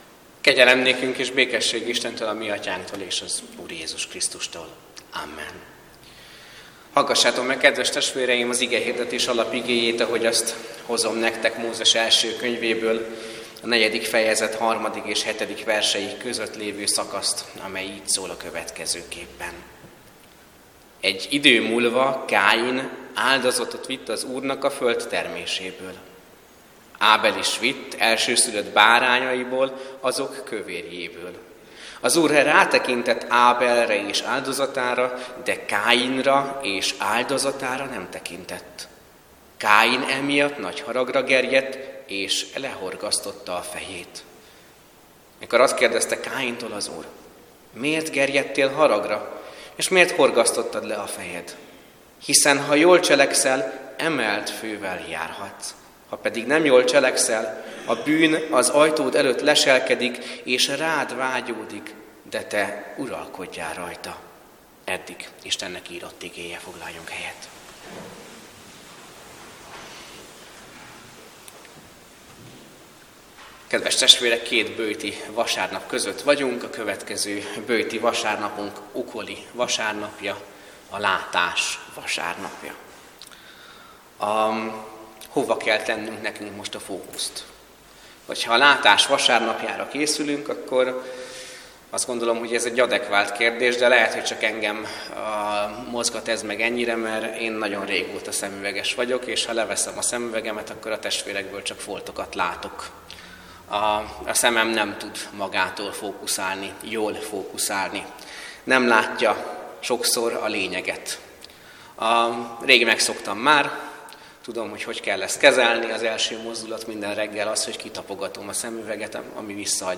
Böjt esti áhítat - Ország, ország, ország!